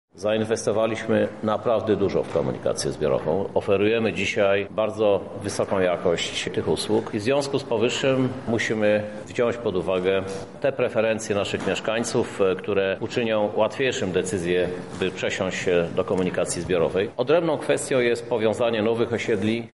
-mówi prezydent miasta Krzysztof Żuk.